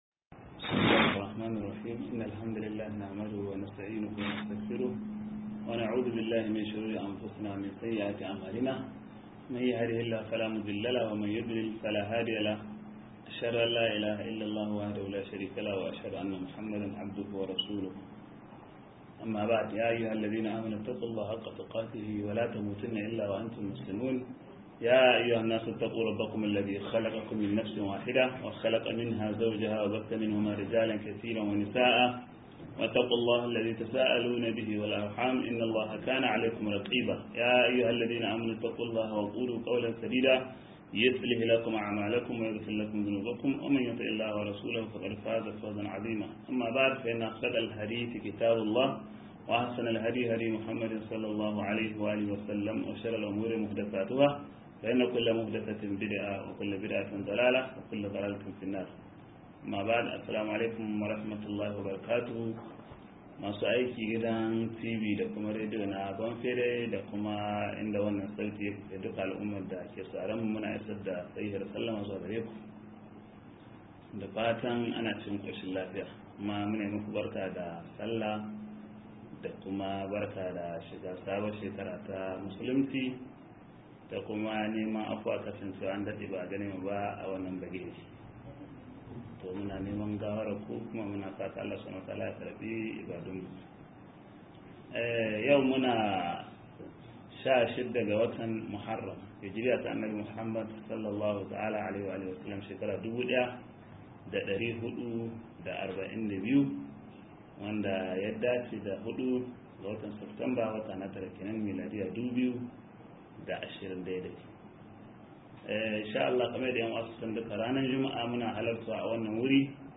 88 - MUHADARA